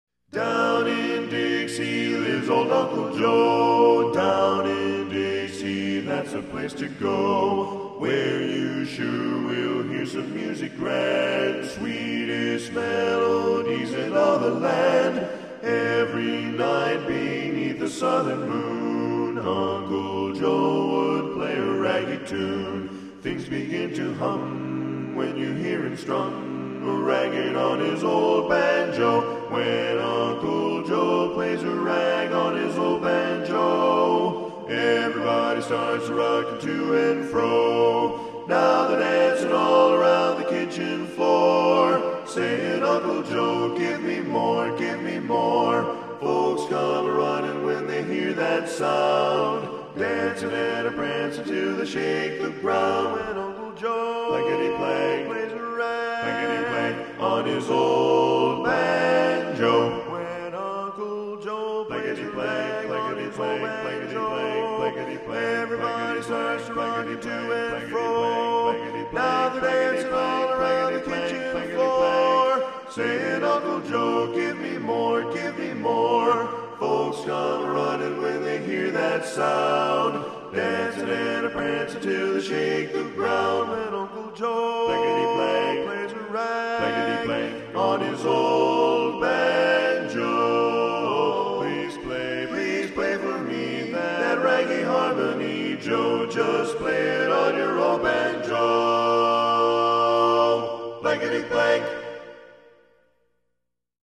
When Uncle Joe Plays a Rag on the Old Banjo Learning Tracks (Part Predominant Midis) Music PDF
Tenor